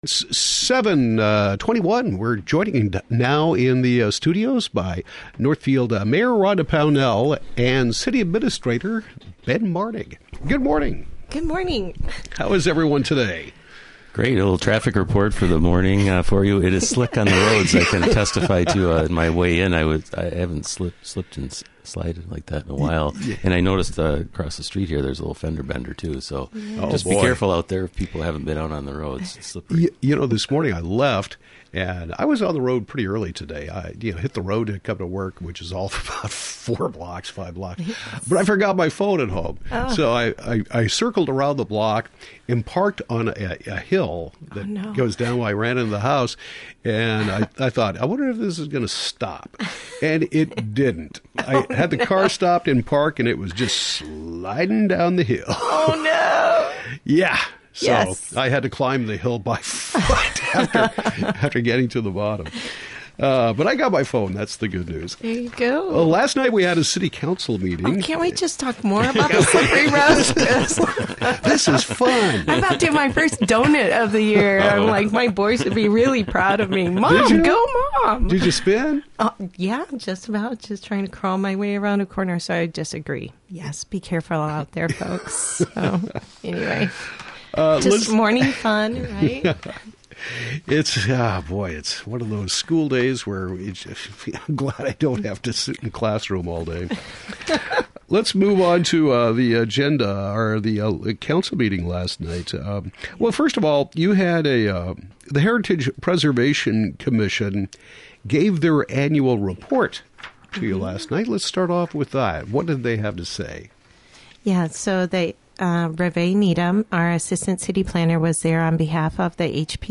Mayor Rhonda Pownell and Ben Martig discuss City Council meeting
Northfield Mayor Rhonda Pownell and City Administrator Ben Martig recap the November 15 City Council meeting. Topics include the Heritage Preservation Commission annual report, professional service agreement for study relating to Ames Mill dam removal, and survey relating to Northfield parks.